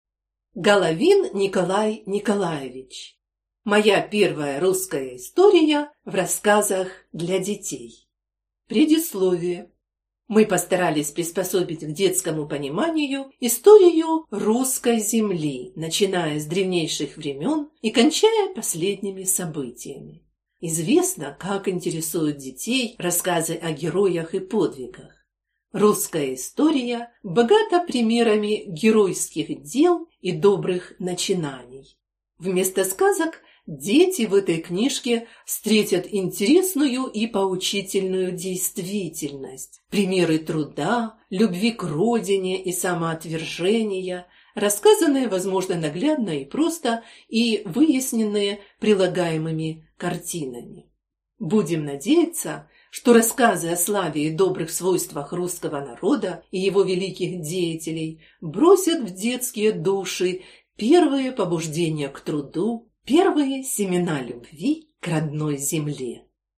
Аудиокнига Моя первая русская история в рассказах для детей | Библиотека аудиокниг